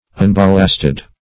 Unballasted \Un*bal"last*ed\, a.